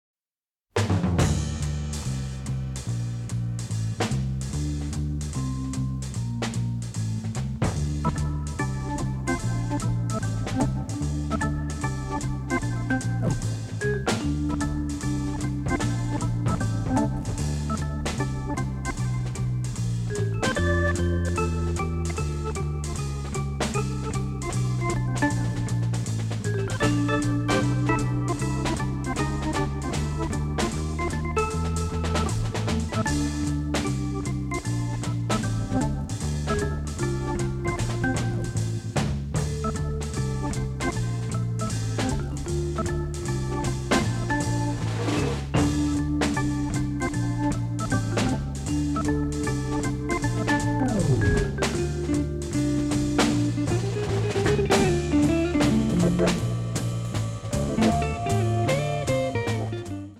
loungesque